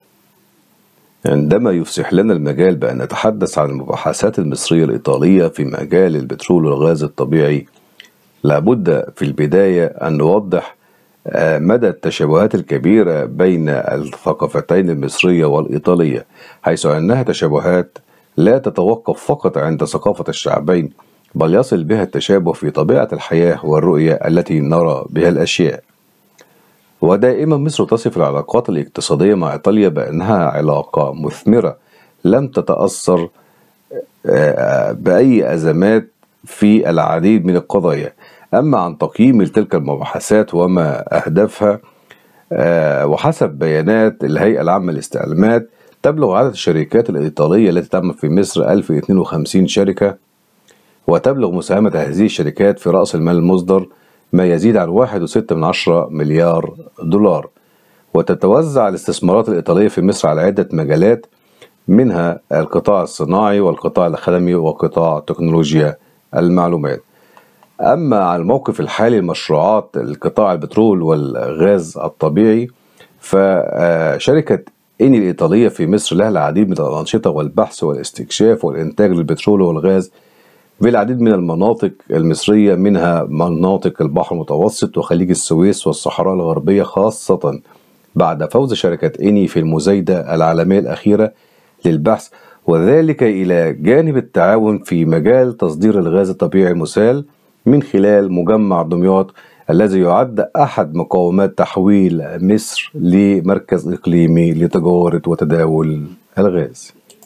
محلل اقتصادي